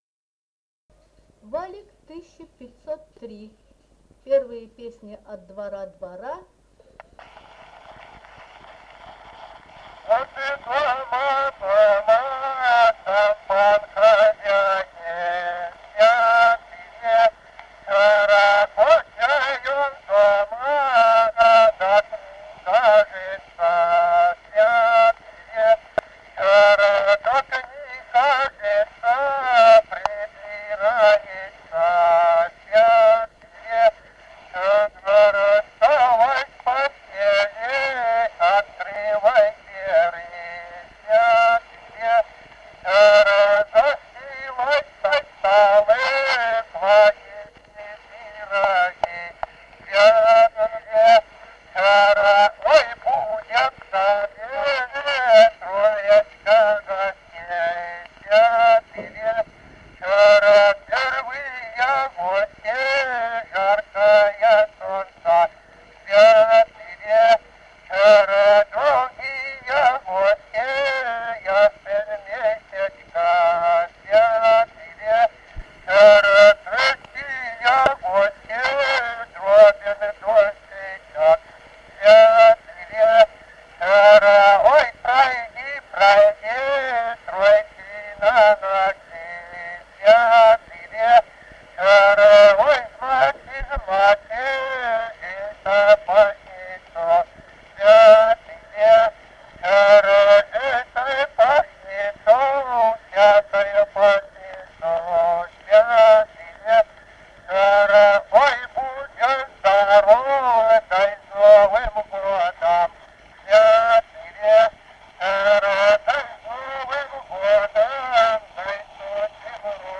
Народные песни Стародубского района «Вот дома, дома», новогодняя щедровная.
д. Камень.